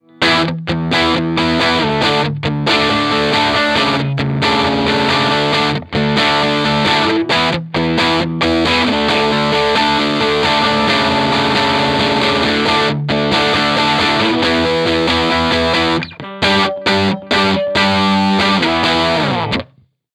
TC-15 DIRTY
Tone Tubby Ceramic
The TC-15 tone was the Ch.1 EF86, "Lo" input, "munch".
TC15_DIRTY_ToneTubbyCeramic.mp3